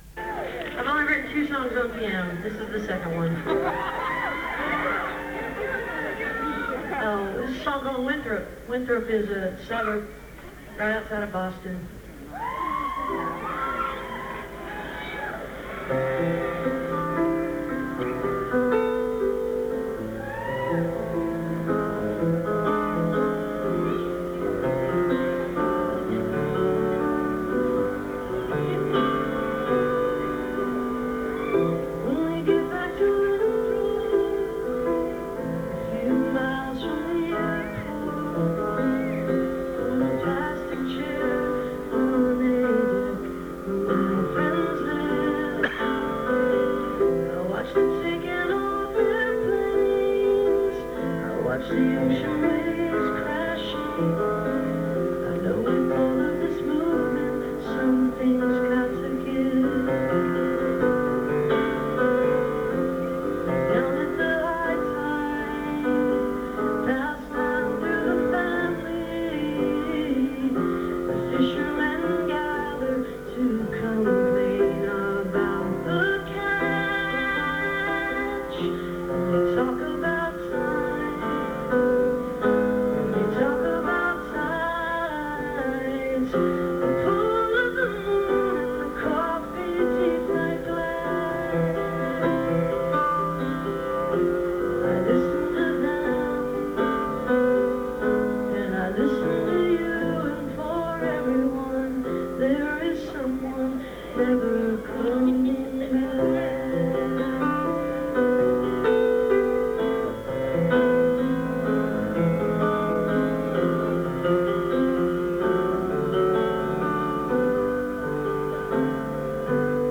the backyard - austin, texas